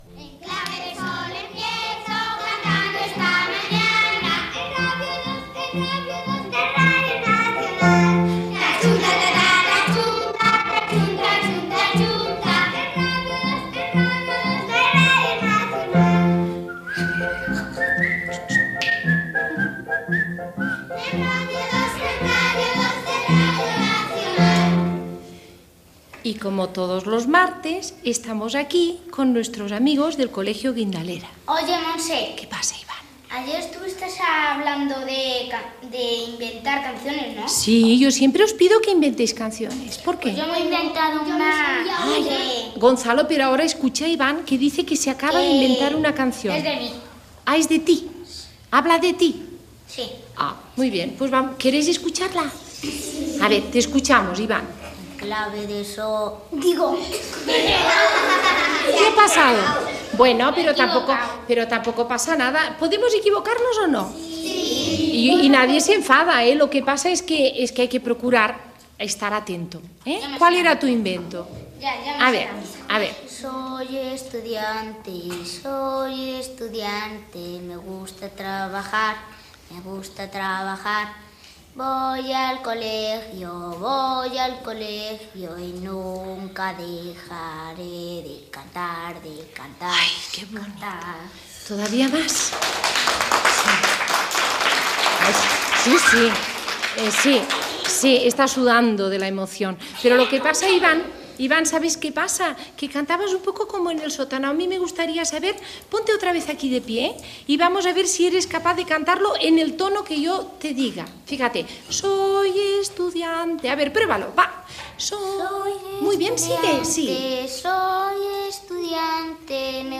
Careta del programa, diàleg inicial sobre inventar cançons i espai dedicat a l'hivern Gènere radiofònic Infantil-juvenil